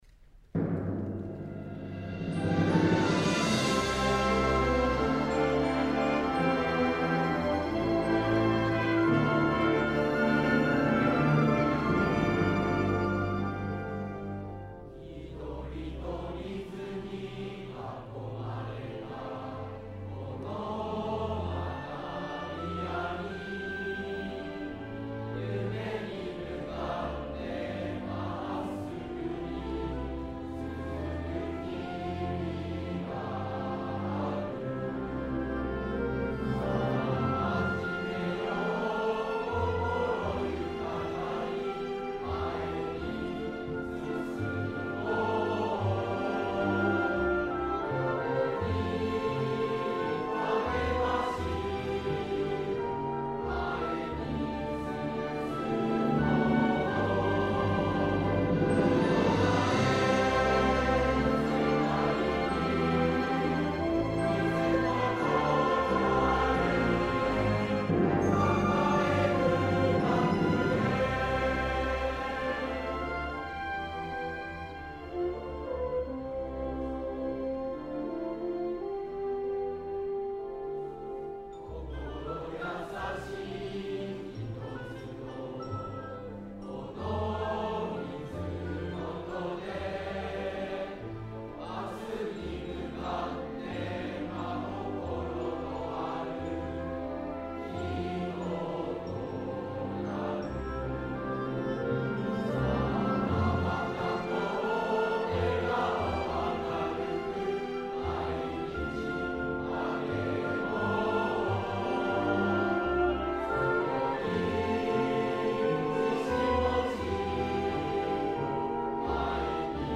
校歌・校章(BGM)